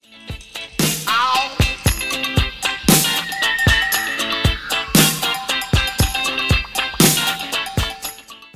The audio clip below contains the scream in question: